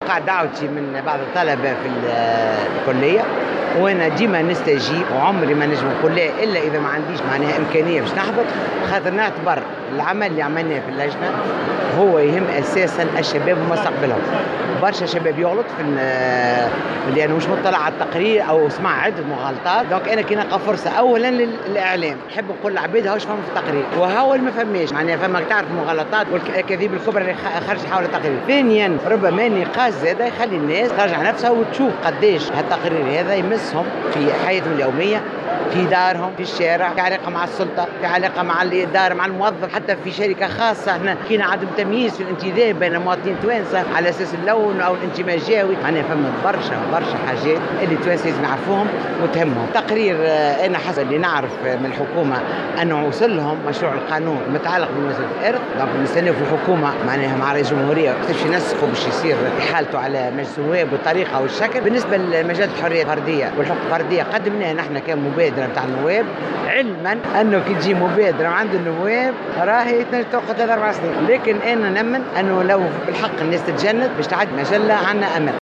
أكدت رئيسة لجنة الحقوق الفردية والمساواة، بشرى بالحاج حميدة، في تصريح للجوهرة أف أم، على هامش ندوة عقدت اليوم الخميس بكلية الحقوق والعلوم السياسية بسوسة، أن مشروع قانون المساواة في الإرث قد أحيل على رئاسة الحكومة، في انتظار قيامها بالتنسيق مع رئاسة الجمهورية لعرضه على أنظار مجلس نواب الشعب.